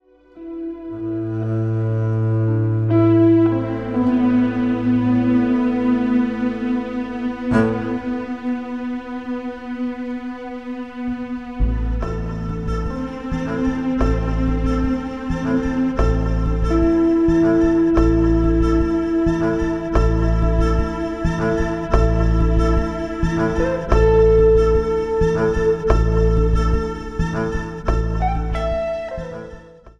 Double Bass